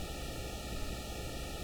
Index of /90_sSampleCDs/Roland LCDP09 Keys of the 60s and 70s 1/ORG_Farfisa Flts/ORG_Farflut Noiz